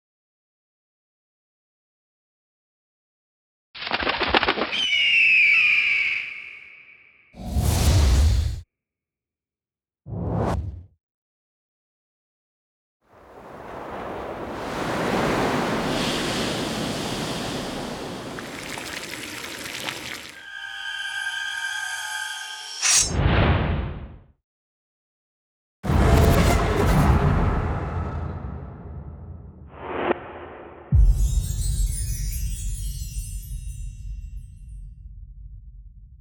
游卡-xgame-孙权SFX.mp3